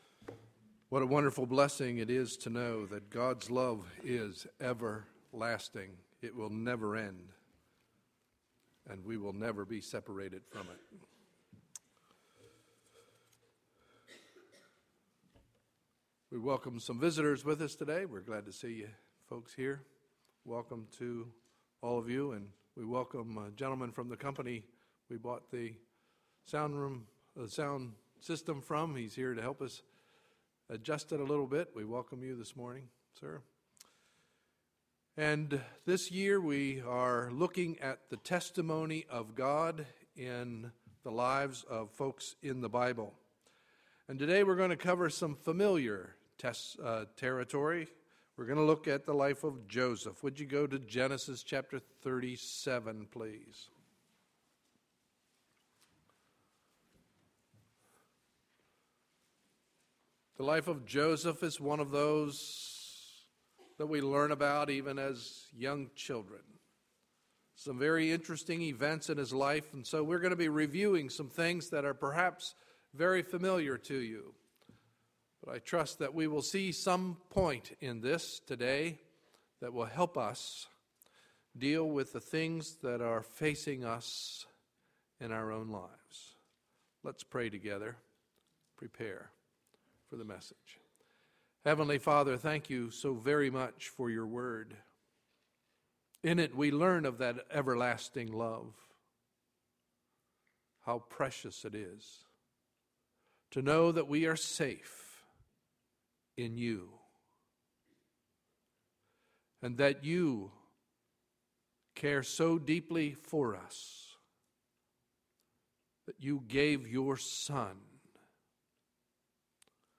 Sunday, March 11, 2012 – Morning Message